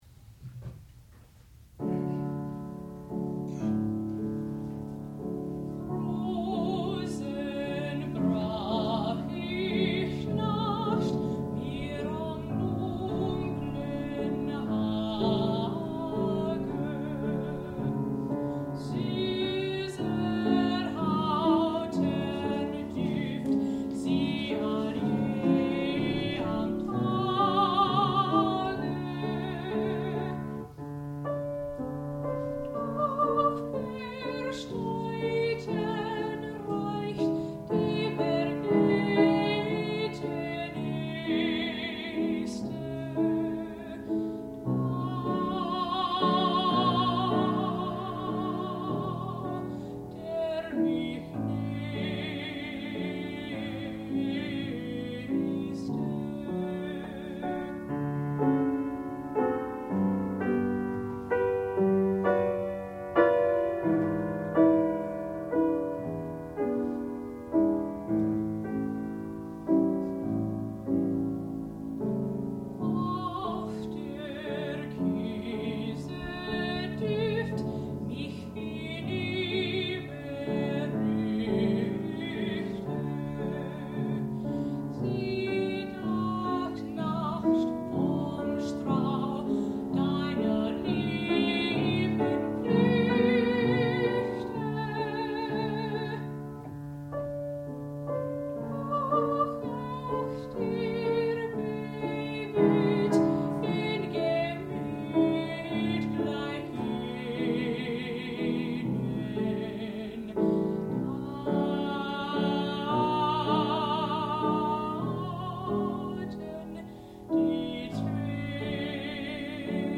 sound recording-musical
classical music
mezzo-soprano
piano
Qualifying Recital